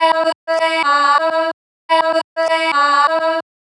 • chopped vocals 109-127 female 1 (10) - Em - 127.wav